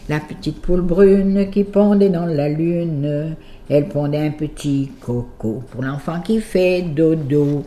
Berceuses diverses
Genre strophique
Pièce musicale inédite